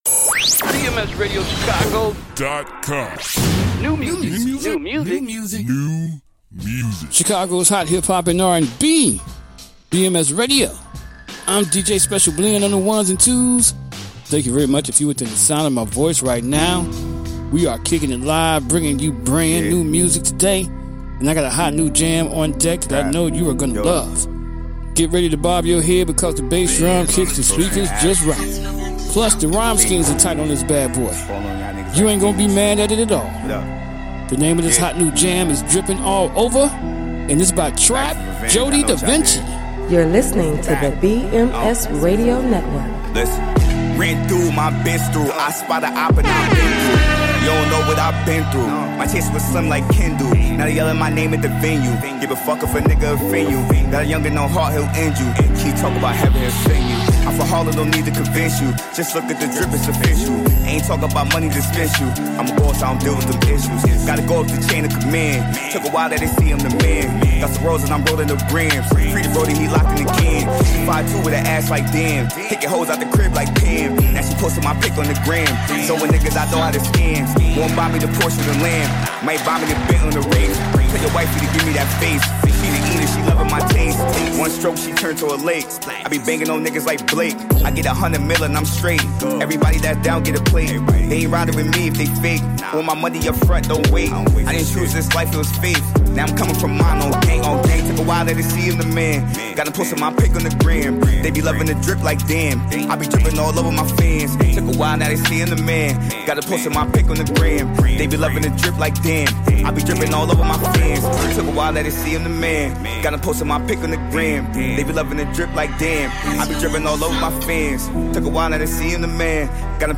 Chicago’s Hot Hip-Hop and R&B.